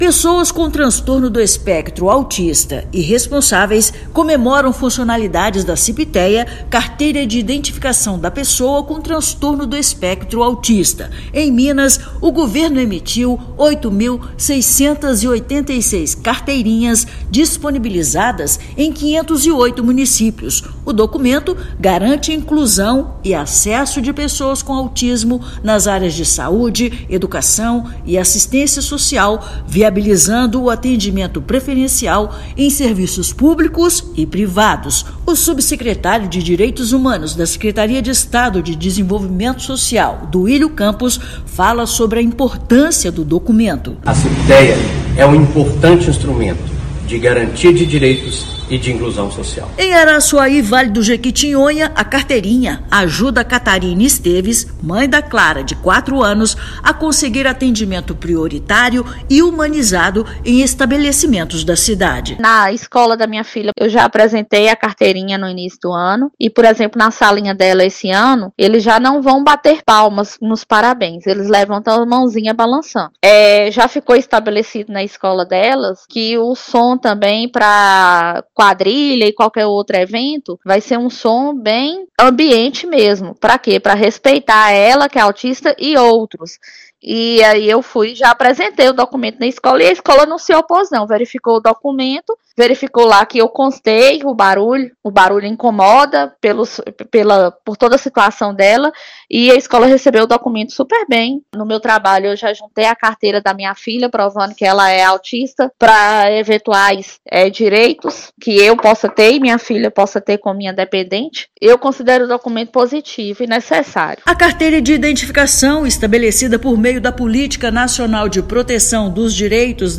Agência Minas Gerais | [RÁDIO] No mês de conscientização sobre o Autismo, Ciptea é sucesso em Minas Gerais
Documento de Identificação da Pessoa com Espectro Autista chega a 59% dos municípios mineiros com 8.686 carteiras já emitidas. Ouça matéria de rádio.